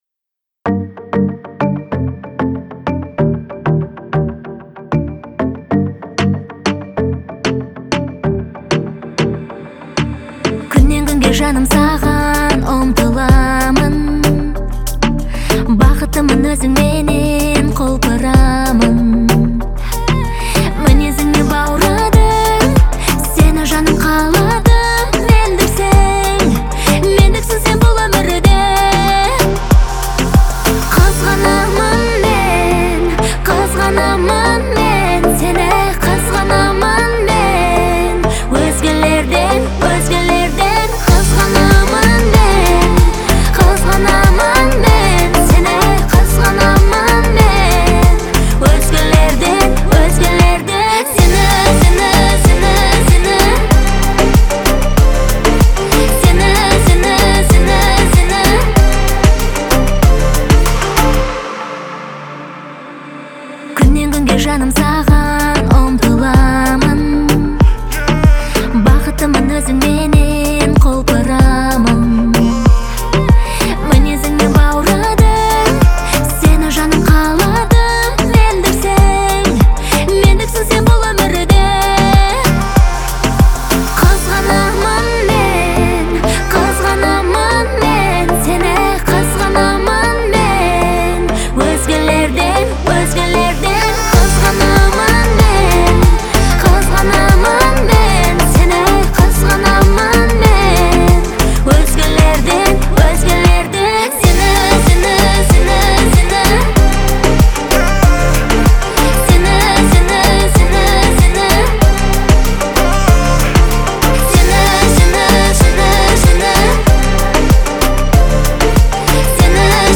яркая и эмоциональная песня казахстанской певицы